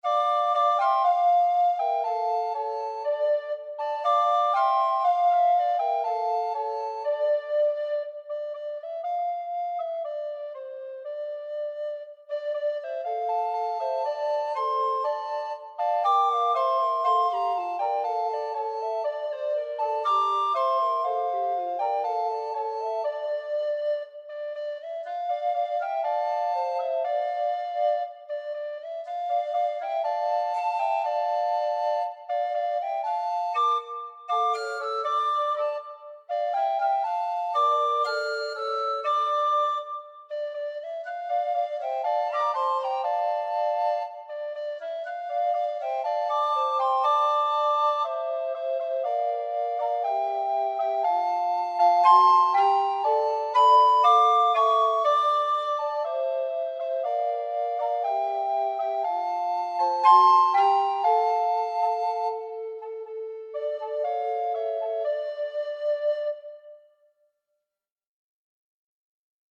Darin sind 5 handgefertigte Recorder, zu deutsch Flöten, enthalten. Das sind im Einzelnen: Bass, Tenor, Alto, Soprano und Sopranonino.
emberton-recorder-rose.mp3